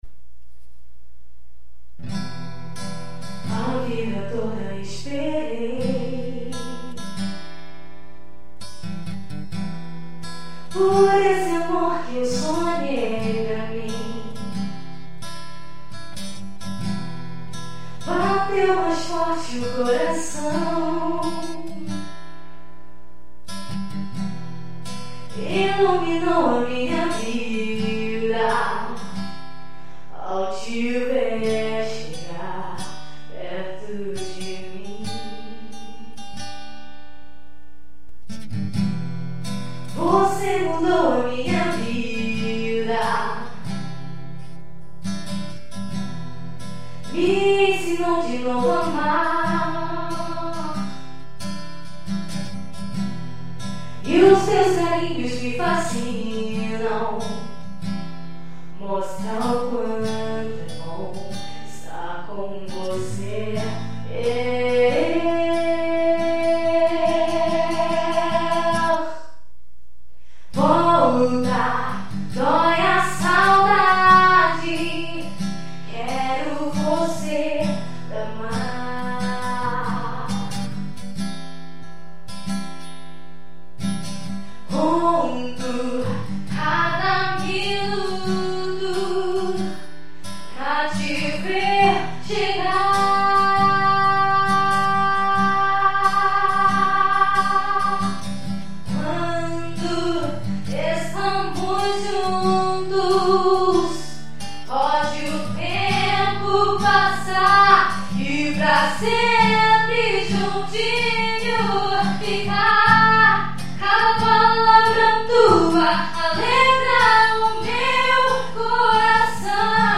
voz e violão.